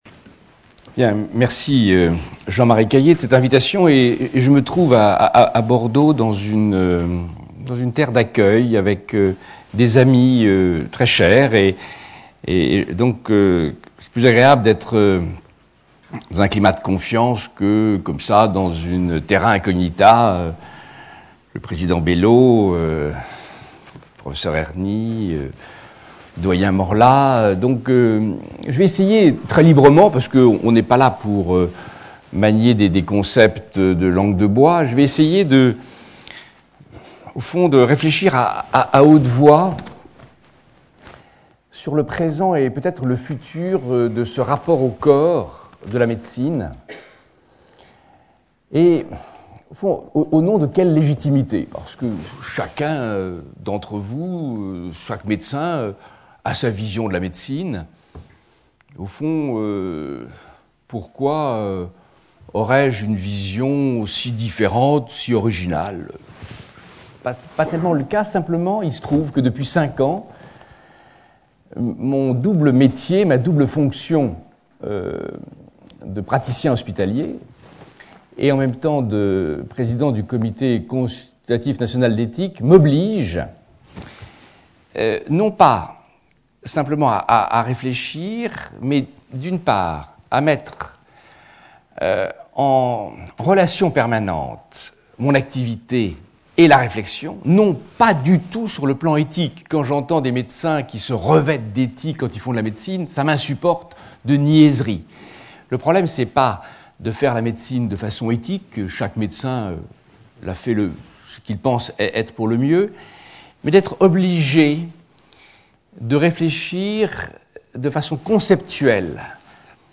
Conférence de D.Sicard sur le sens de la médecine contemporaine face à la technique.
La médecine triomphante influence aussi les juges qui justifient ainsi les procès. La conférence a été donnée à l'Université Victor Segalen Bordeaux 2 dans le cadre du cycle de conférences "L'invité du Mercredi" / Saison 2003-2004 sur le thème "Demain".